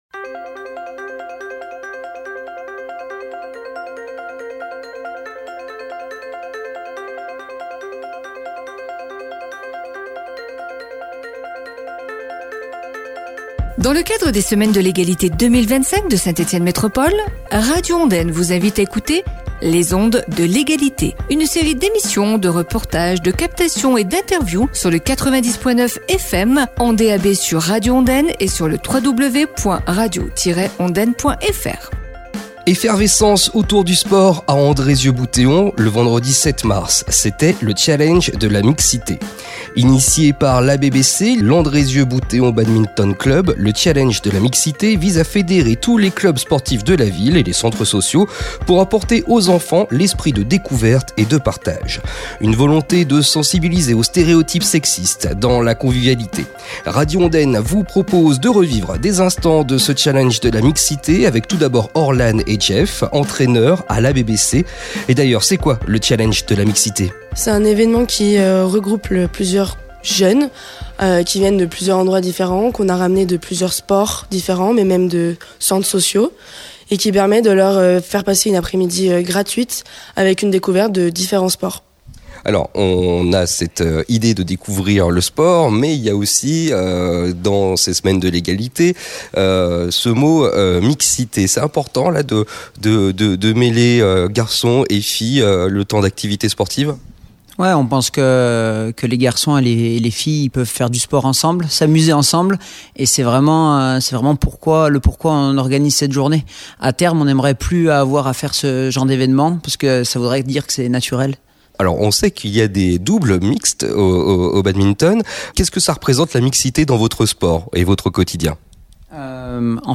Avec le concours des clubs sportifs de la ville, c’est une après-midi où le sport était prétexte à réfléchir sur la mixité, le handicap, l’esprit d’équipe… Reportage à découvrir
Pierre-Julien Marret, adjoint en charge des sports